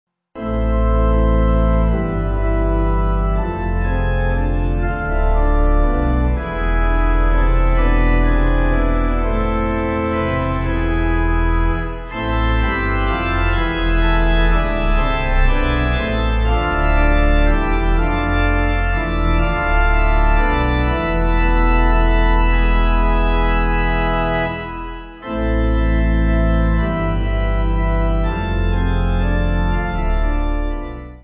Yiddish folk tune